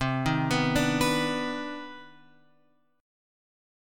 CmM9 chord {8 6 9 7 x 7} chord